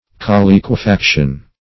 Search Result for " colliquefaction" : The Collaborative International Dictionary of English v.0.48: Colliquefaction \Col*liq`ue*fac"tion\, n. [L. colliquefactus melted; col- + liquefacere; liqu[=e]re to be liquid + facere to make.] A melting together; the reduction of different bodies into one mass by fusion.